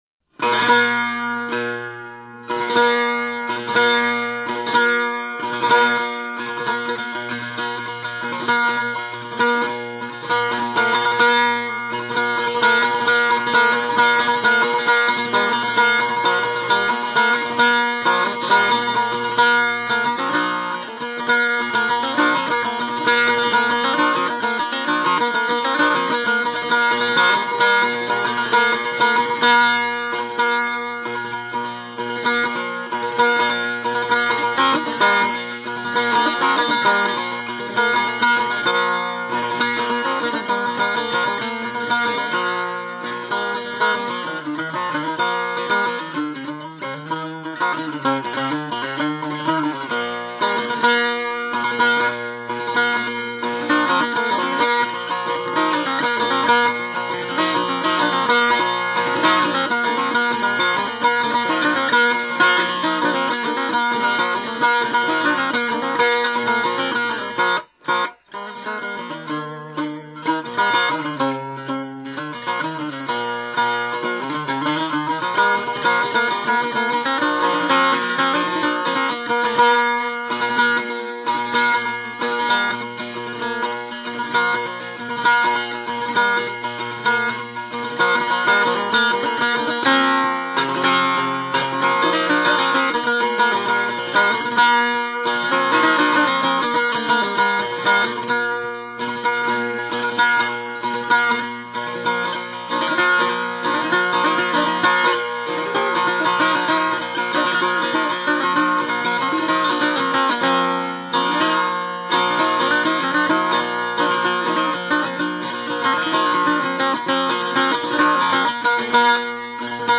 گردش نغمات در گوشه های مختلف دستگاه ماهور بر جذابیت این چهار مضراب افزوده است.
شیوه نوازندگی ایشان مبتنی بر شیوه قدما، همراه با مضراب های به اصطلاح پر و چپ های قوی است.